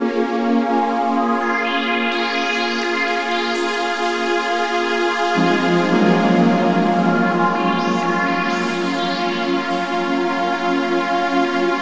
03D-PAD-.A-L.wav